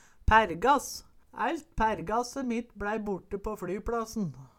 pærrgass - Numedalsmål (en-US)